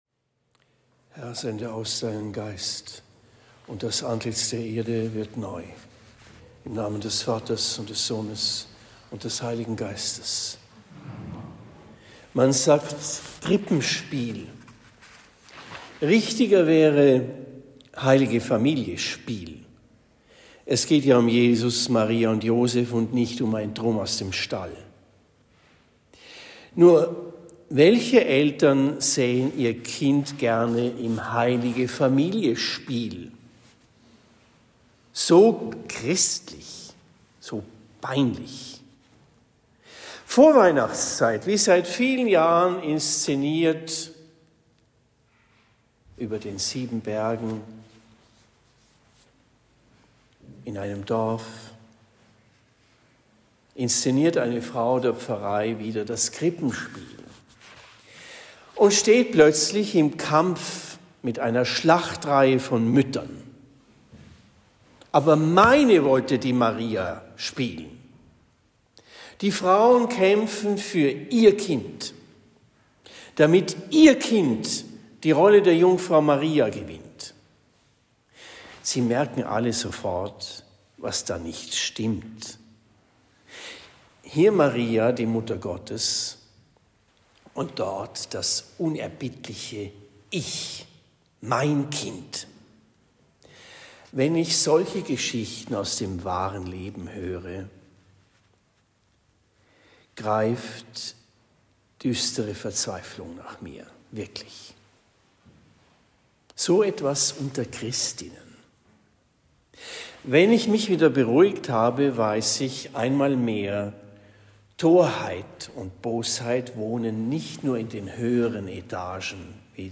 Predigt in Marienbrunn am 28. Dezember 2024